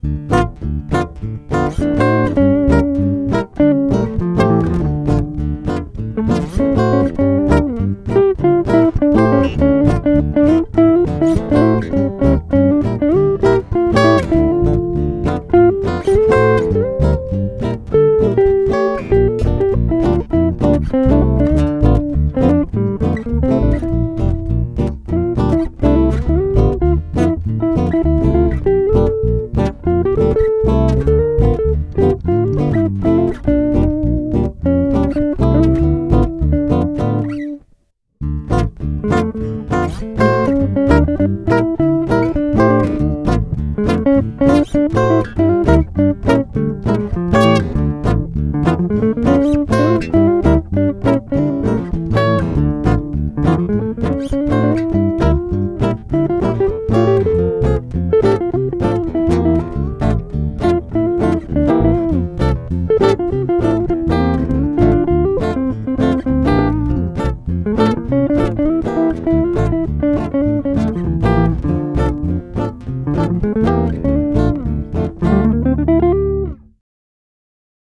Jeden Tag im Oktober ein Musikstück für die richtige Halloween-Stimmung.